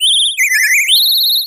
rookidee_ambient.ogg